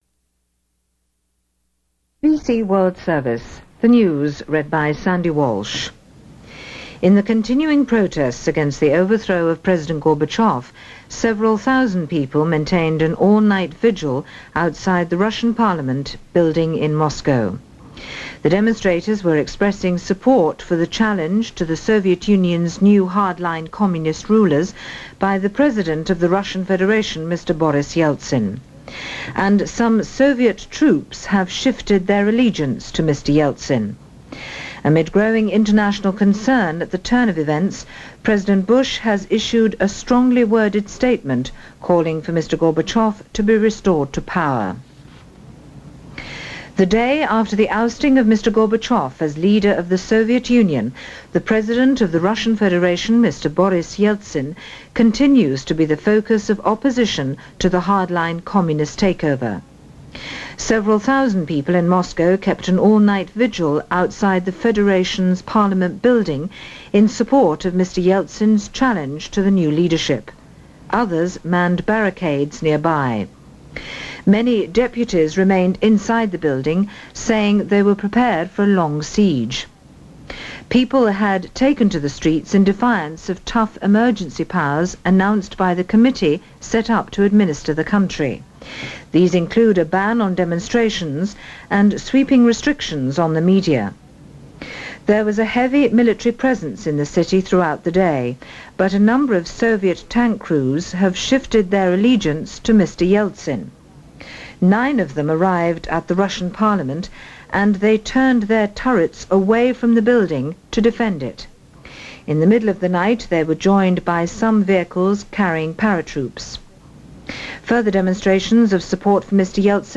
August 20, 1991 - In Walked Yeltsin - The attempted Coup in Russia - Day 2 - BBC World Service reports, this day in 1991.